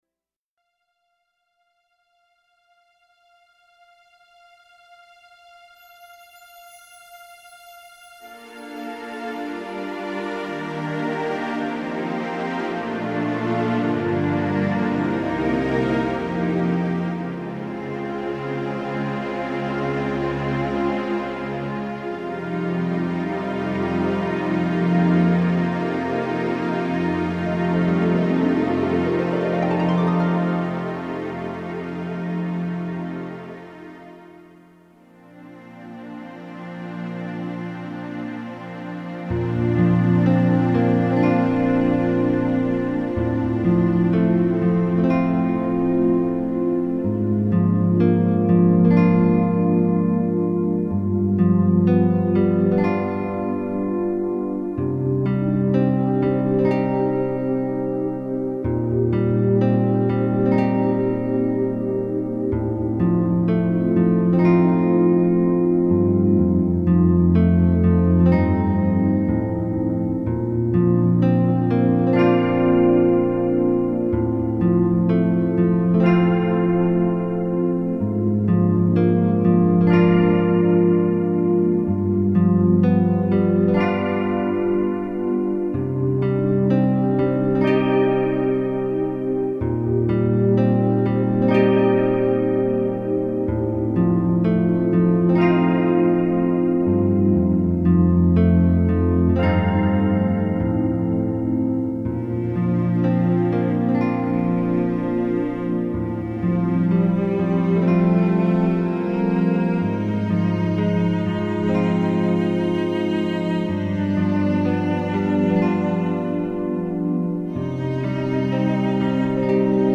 イメージトレーニング用CD曲目
７曲目以降は、音楽(BGM)のみのトラックです。